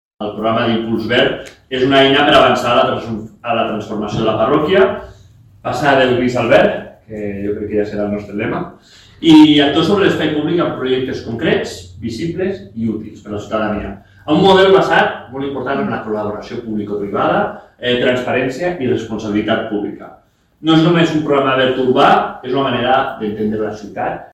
Per aquest motiu, la corporació ha presentat aquest dimecres el nou Programa d’Impuls Verd, una iniciativa destinada a transformar l’espai urbà de la capital cap a un model més sostenible, confortable i adaptat als reptes climàtics, tal com explica el cònsol major, Sergi González.